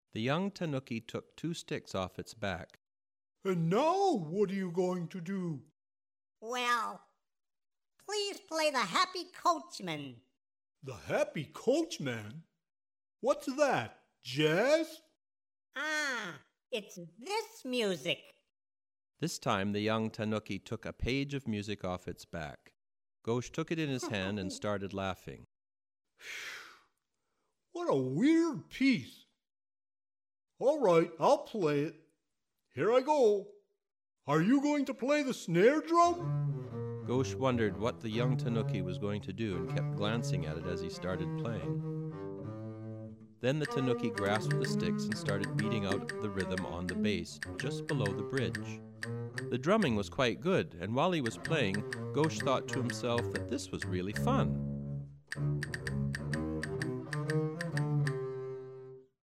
An audio book with music. Kenji Miyazawa's well-known and well-loved story, "Gauche The Cellist", comes to life once again in this delightful adaptation for the double bass.